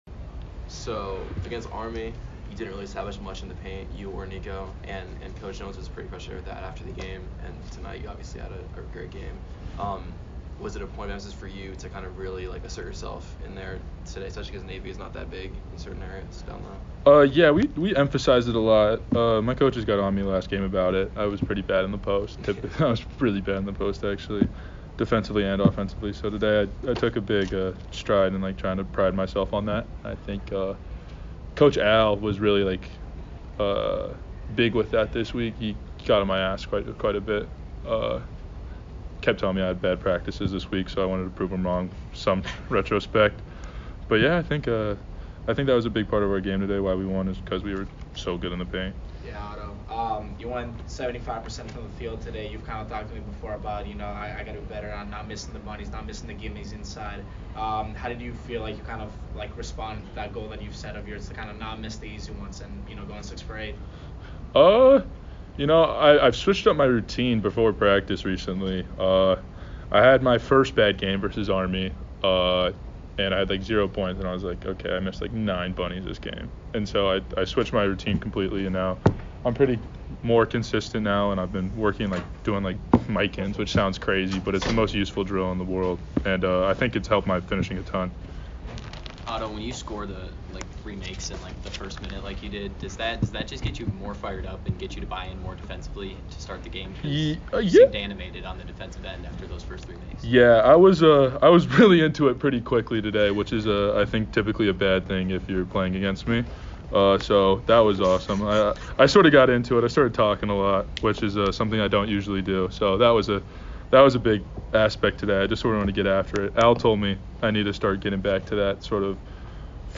Navy Postgame Interview